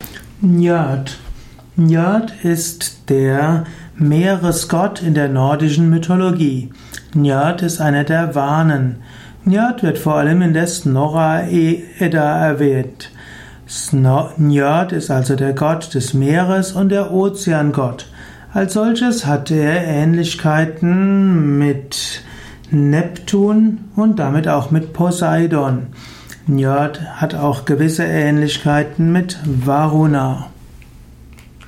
Ein Audio Vortrag über Njörd, einem germanischen Gott. Mit Überlegungen über die Bedeutung von Njörd in der germanischen Mythologie, im germanischen Götterhimmel.
Dies ist die Tonspur eines Videos, zu finden im Yoga Wiki.